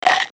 MG_sfx_vine_game_bananas.ogg